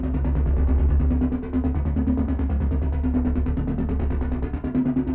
Index of /musicradar/dystopian-drone-samples/Tempo Loops/140bpm
DD_TempoDroneE_140-D.wav